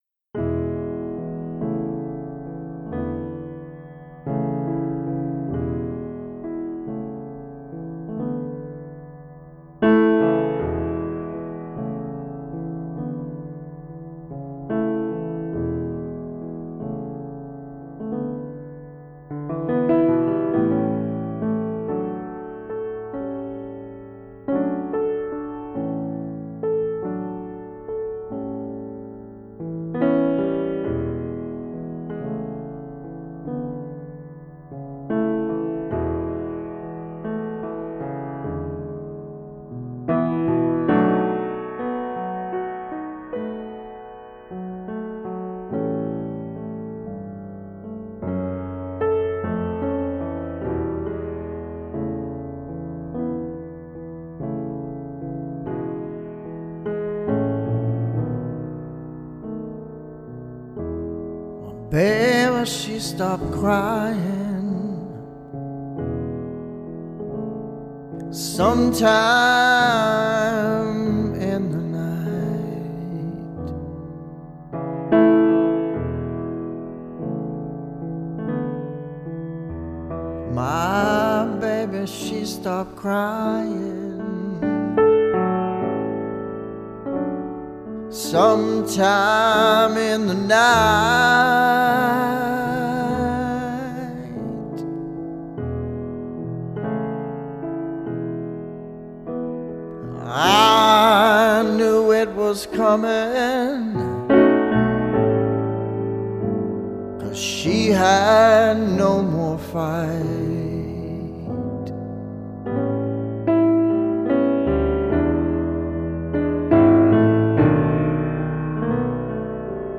Тип альбома: Студийный
Жанр: Blues-Rock, Soft-Rock